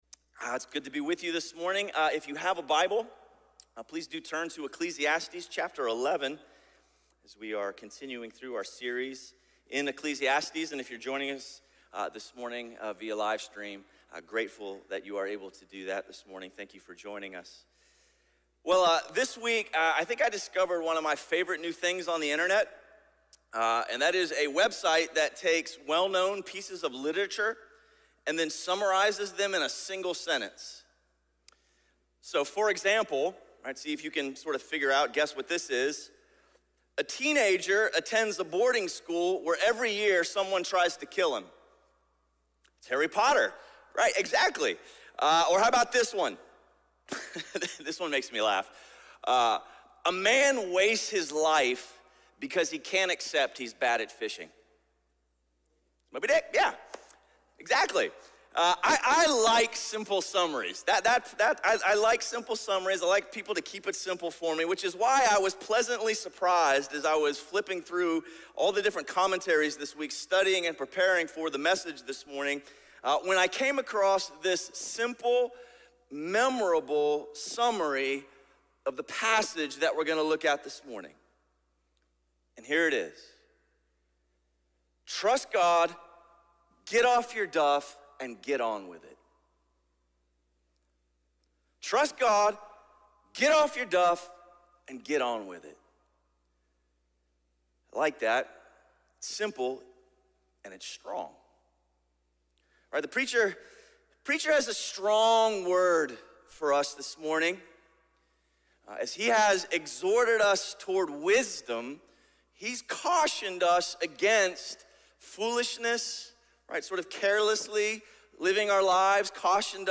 Sermon series through the book of Ecclesiastes.